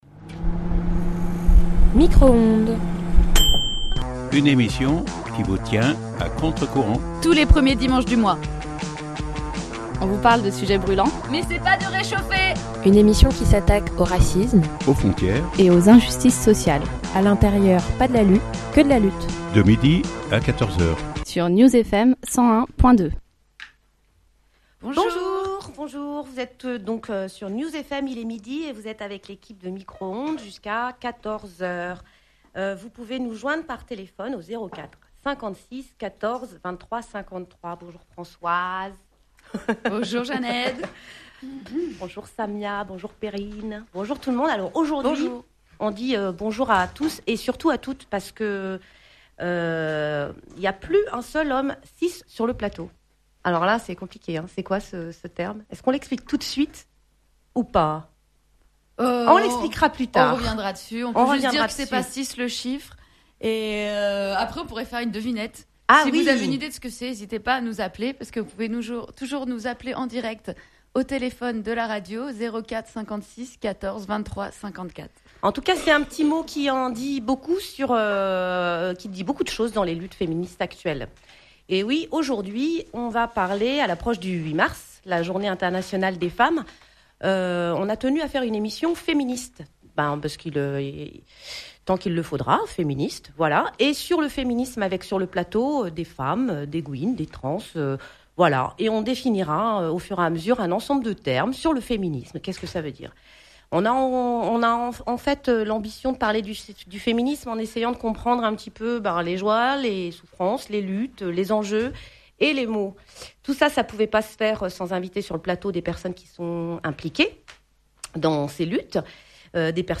Micro-Ondes est une émission de radio diffusée tous les premiers dimanches du mois de 12h à 14h, sur New’s FM (101.2FM).